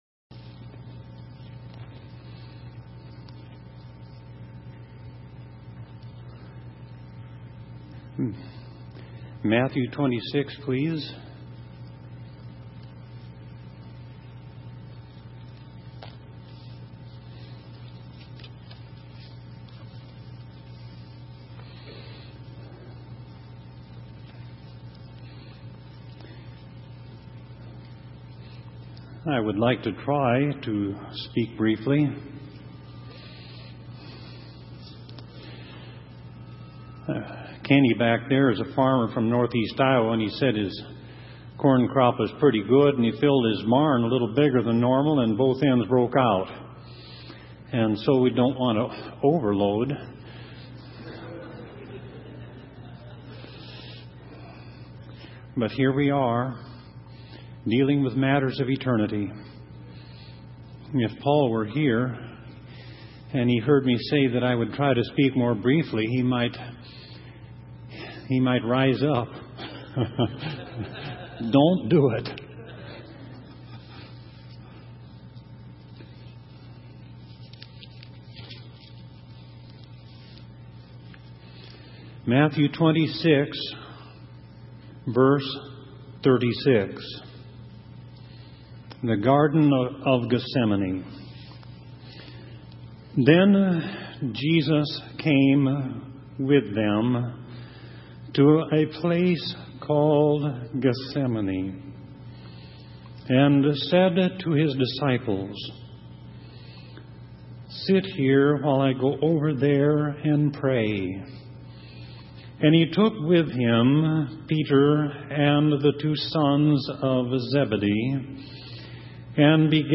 In this sermon, the speaker reflects on the pivotal moment in history when Jesus was about to face his crucifixion.